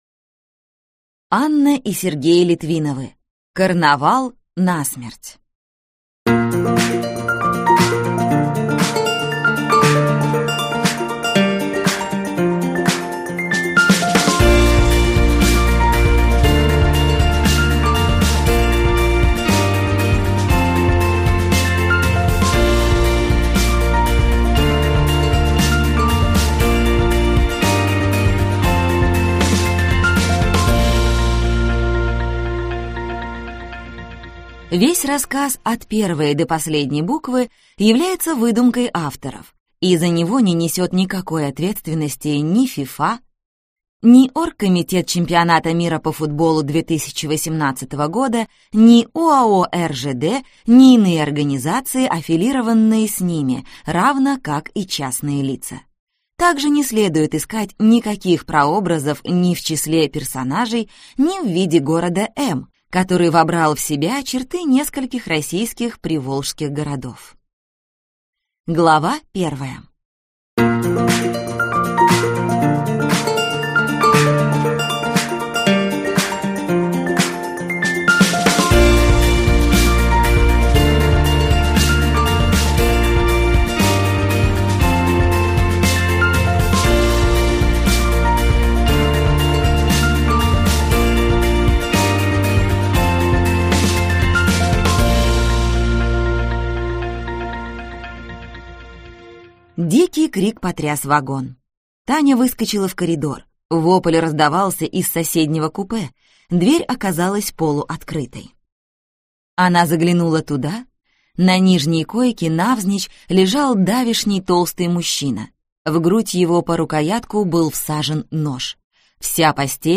Аудиокнига Карнавал насмерть (сборник) | Библиотека аудиокниг